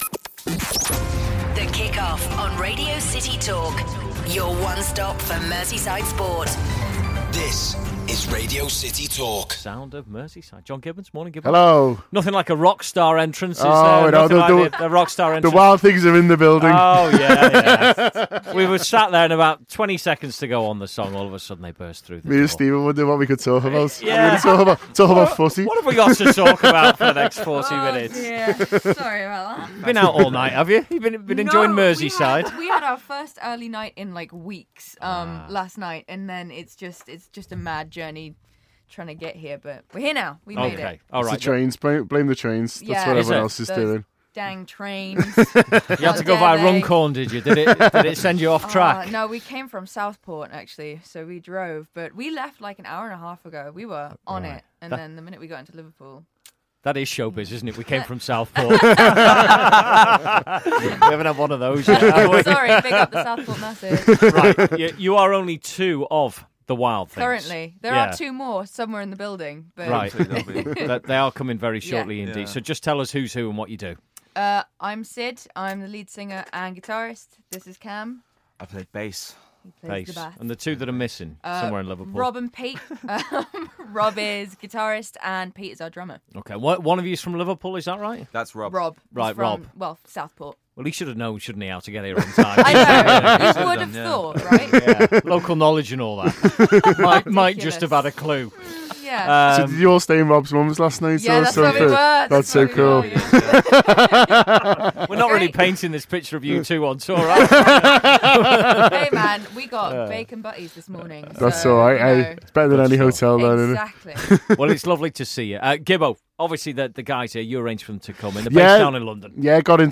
The Wild Things are in the studio for Sound of Merseyside